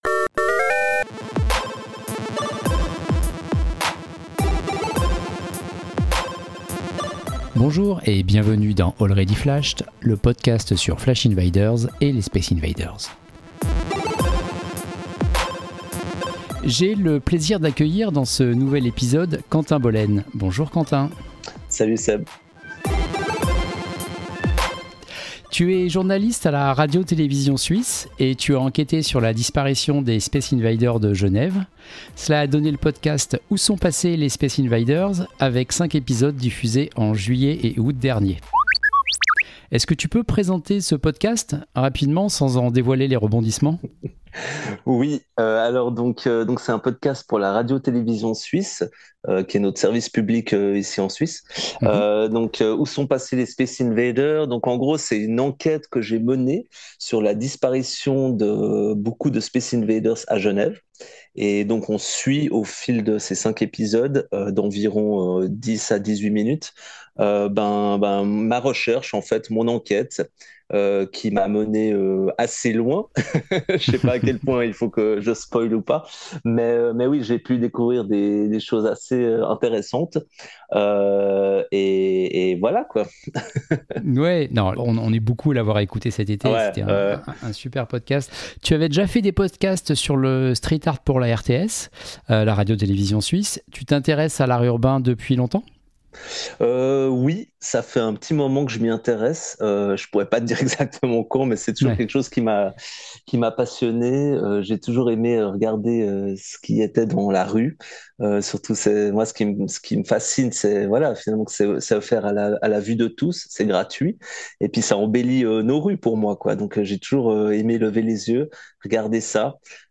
Already Flashed n° 51 – Interview